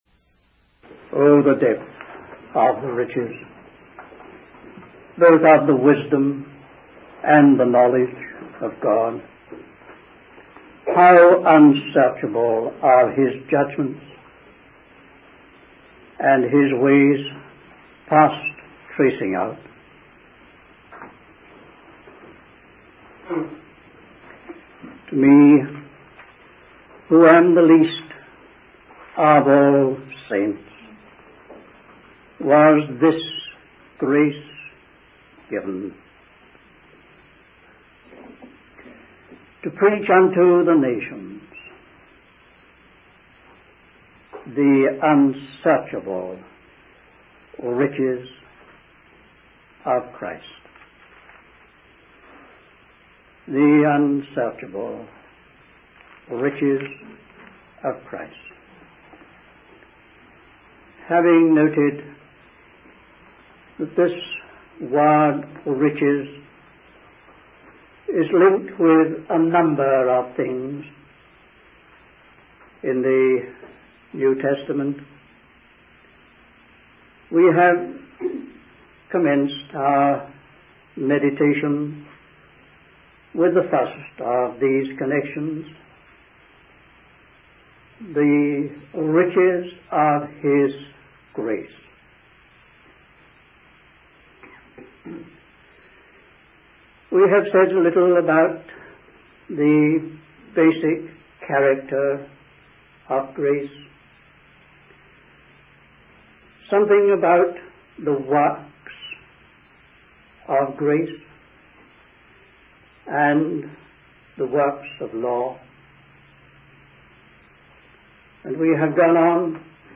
In this sermon, the speaker emphasizes the importance of recognizing that the source of grace is God. They highlight three aspects of grace: the spirit of grace, the spirit of life, and the spirit of power.